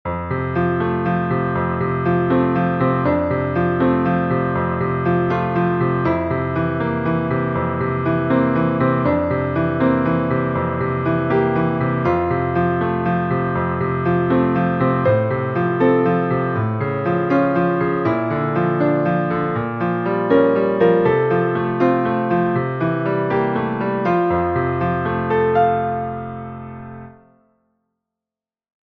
Tonalità: fa maggiore
Metro: 3/4
spartiti pianoforte